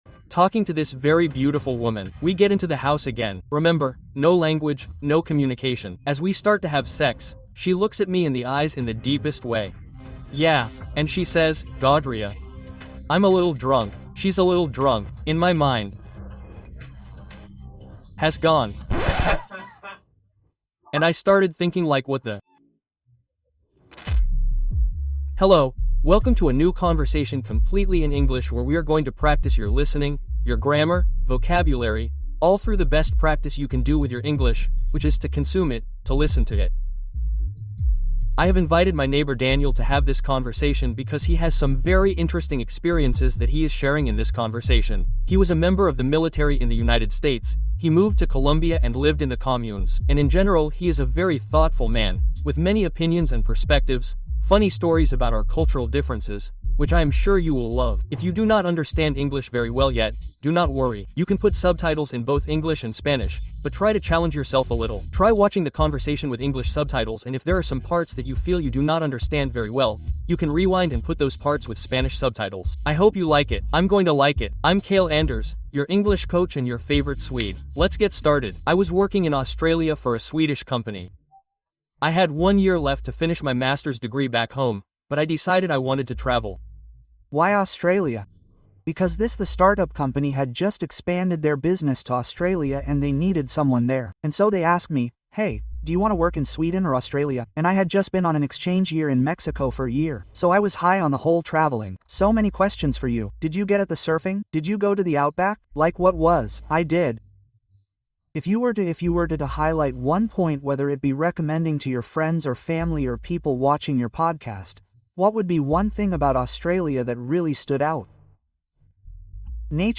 Aprende inglés rápido escuchando una conversación que revela trucos ocultos